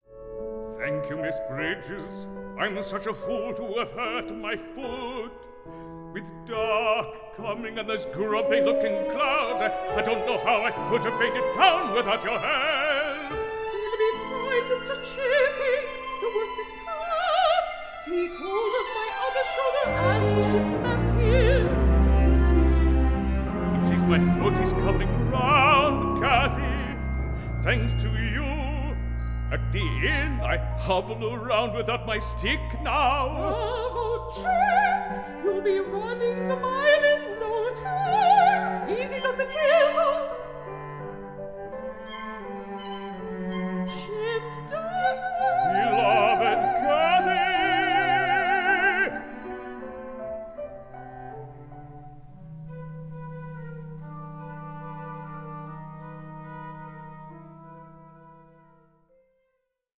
A FEEL-GOOD OPERA ABOUTTHE TEACHER WE ALL WISH WED HAD
new studio recording